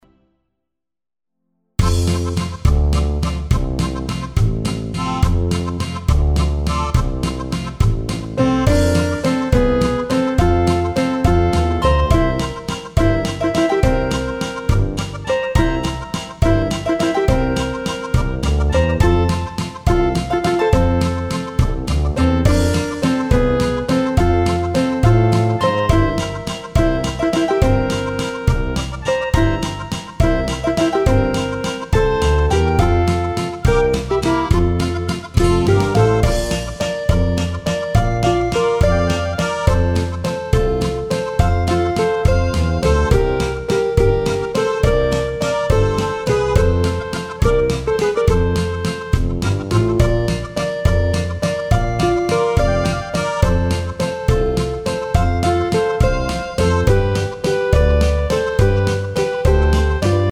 Schunkellied